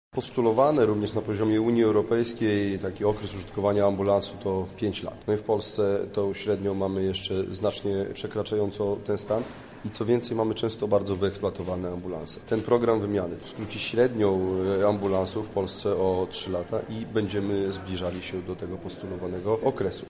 Jak mówi Podsekretarz Stanu w Ministerstwie Zdrowia Sławomir Gadomski, w ramach tej inicjatywy zostaną wymienione przede wszystkim najstarsze karetki, z dużymi przebiegami. Dodaje, że w Polsce ambulanse wciąż służą dłużej niż zaleca to Unia Europejska.